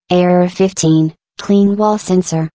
glados_dreame_voice_pack_customized